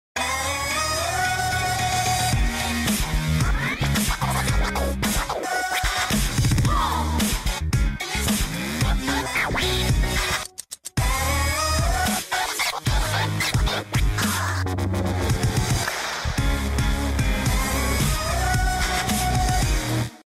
Générique de quelle émission ?